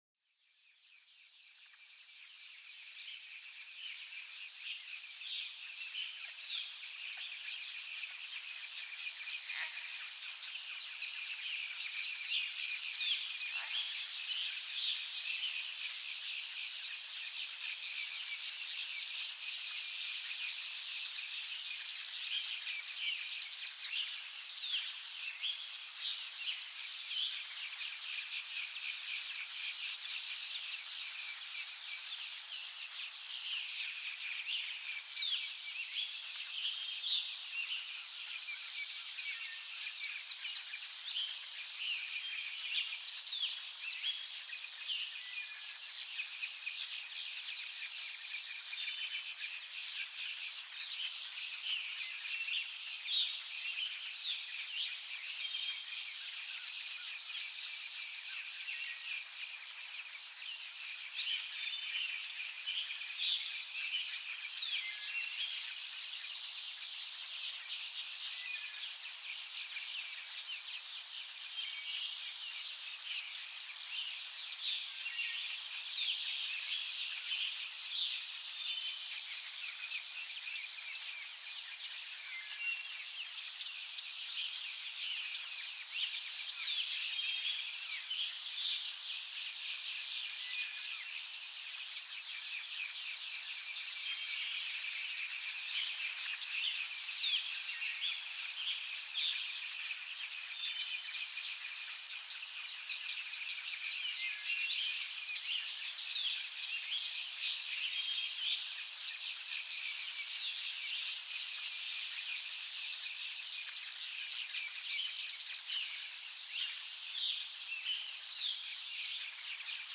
Sumérgete en la calma reconfortante del bosque, donde el susurro de las hojas y el canto de los pájaros crean una atmósfera serena, este episodio te lleva en un viaje auditivo a través del bosque, capturando la esencia de su tranquilidad y belleza natural, déjate arrullar por los sonidos armoniosos de la naturaleza, un verdadero refugio para la mente y el alma.Este podcast es una experiencia de audio inmersiva que sumerge a los oyentes en los maravillosos sonidos de la naturaleza.
SONIDOS DE LA NATURALEZA PARA LA RELAJACIÓN